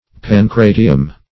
Pancratium \Pan*cra"ti*um\, n. [L., fr. Gr. pagkra`tion a